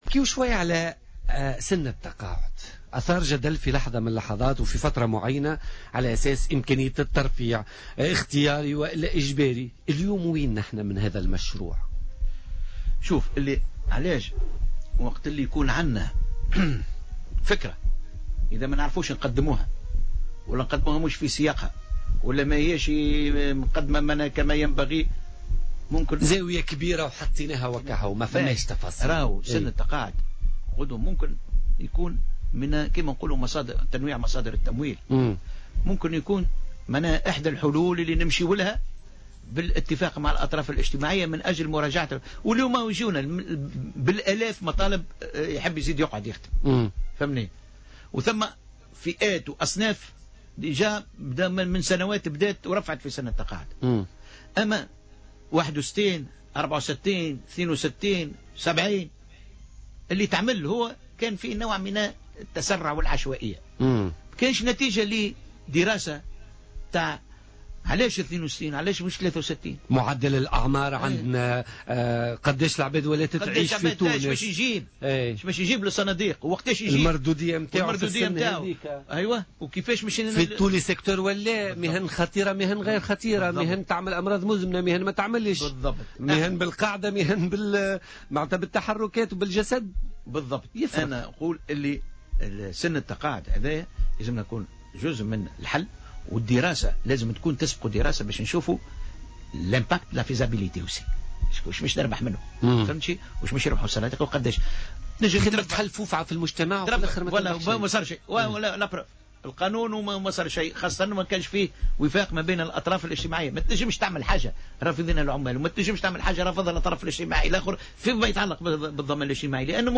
و أضاف محمد الطرابلسي، ضيف برنامج "بوليتيكا" اليوم الثلاثاء أن إصلاح منظومة الضمان الاجتماعي يتطلب جملة من الإجراءات من ذلك تنويع مصادر تمويل الصناديق الاجتماعية و إعادة النظر في سن التقاعد وتوحيد بعض الانظمة في مختلف الصناديق وغيرها من الإجراءات الأخرى.